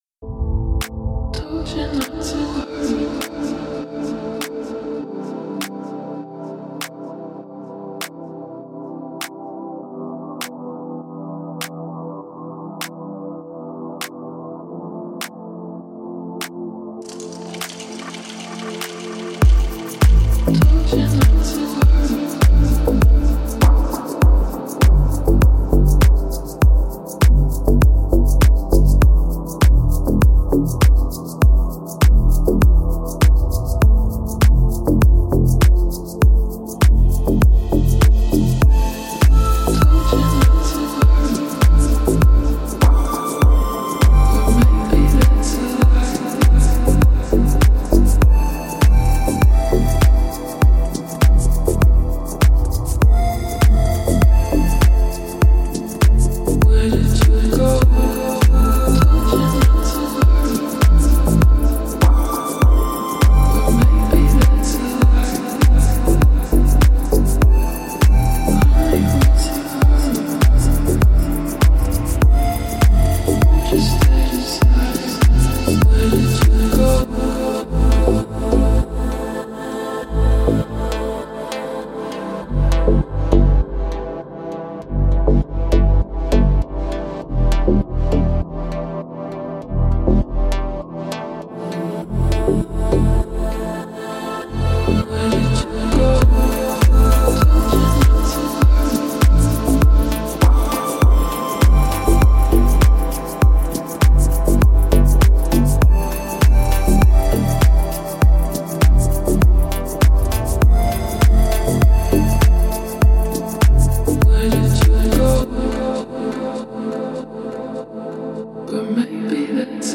минималистичные аранжировки